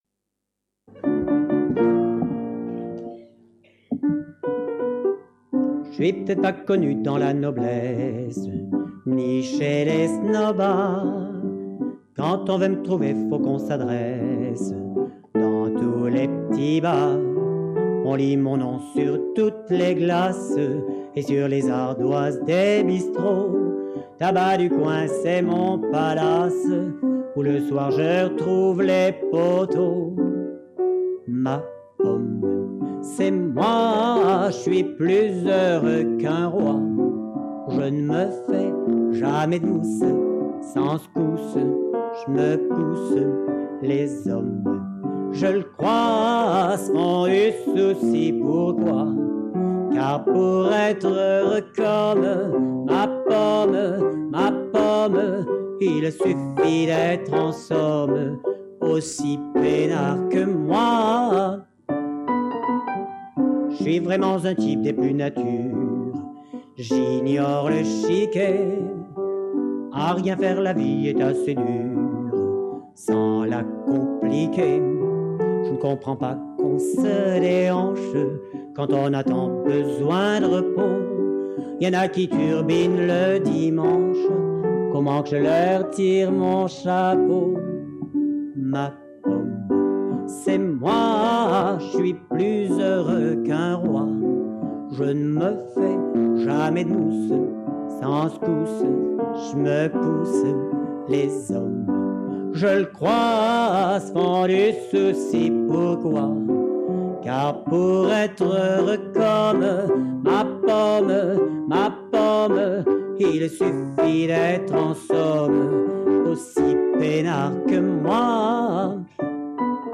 Chanson enregistrée en public en 1985